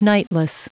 Prononciation du mot nightless en anglais (fichier audio)
Prononciation du mot : nightless